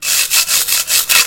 描述：由木材对金属的刮擦而形成。